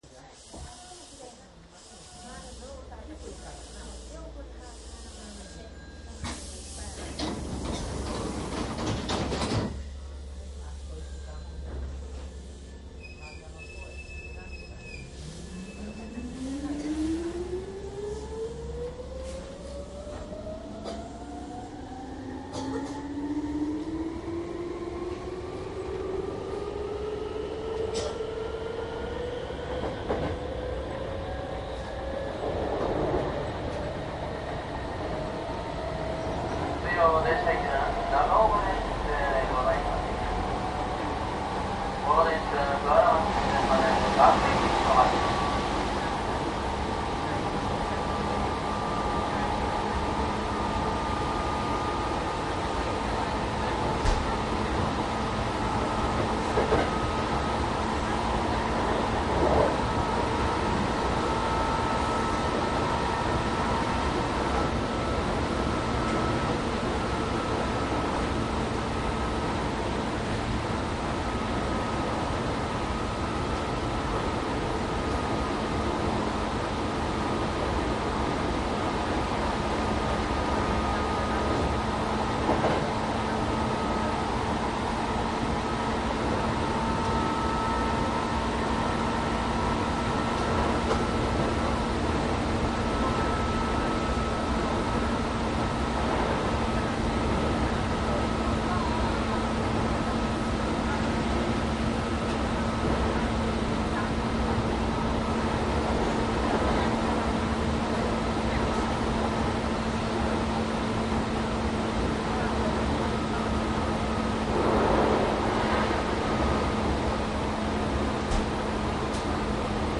♪阪急3300系・5300系 走行音 ＣＤ
阪急京都線で3300系と5300系の走行音を集めたものです。
■【普通】高槻市→河原町 3339＜DATE02-1-13＞
MDかDATの通常SPモードで録音（マイクＥＣＭ959）で、これを編集ソフトでＣＤに焼いたものです。